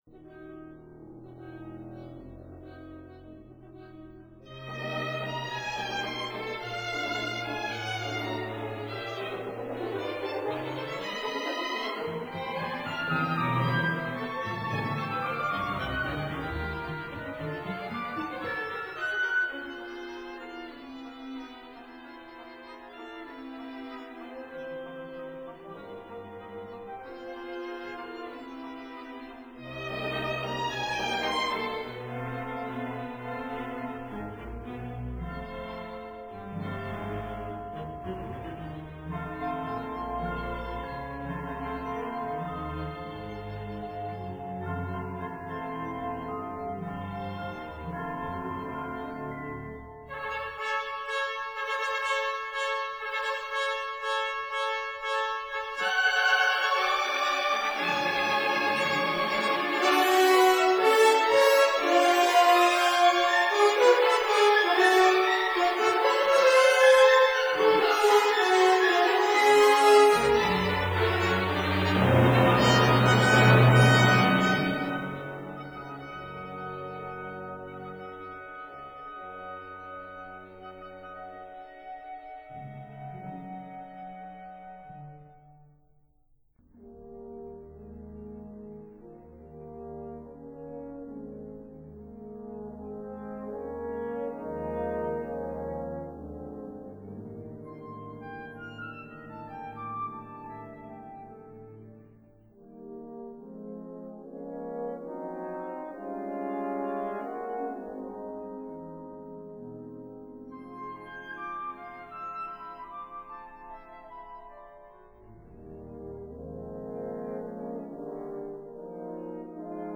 ballet in 3 acts
piano